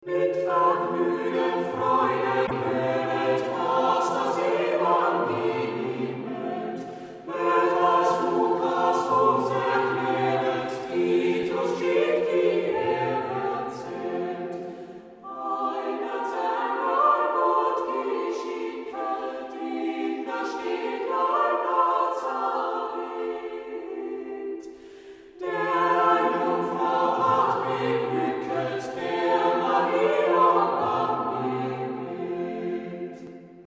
contribute to a contemplative atmosphere